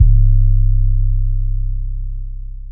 kits/TM88/808s/THE ORIGINAL 808 MAFIA 808.wav at 32ed3054e8f0d31248a29e788f53465e3ccbe498